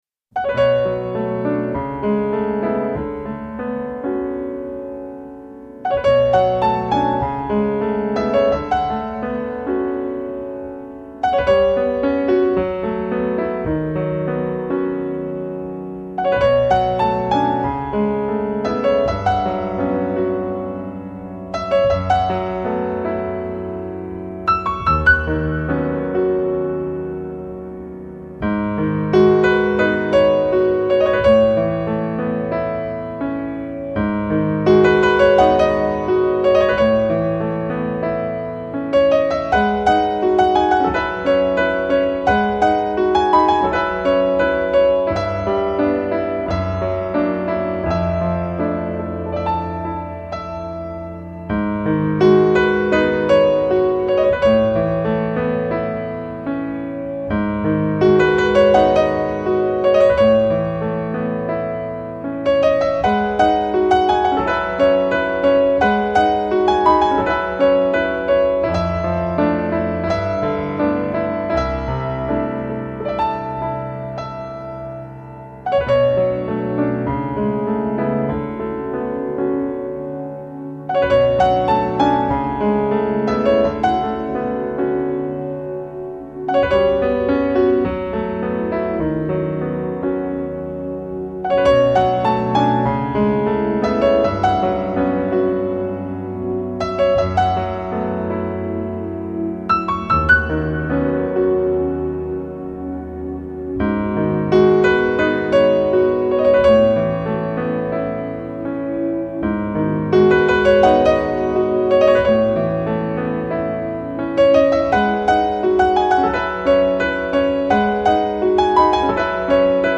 ここでセレクトした曲はアジアをテーマにしたリラグゼーションミュージッック。
アジアのソフトな音楽はそんなやさしさを思わせてくれる温かい响きをもつ。
所有10轨曲目的选取都偏向治愈风格的轻音乐，很适合平时在家练习“瑜伽”时播放。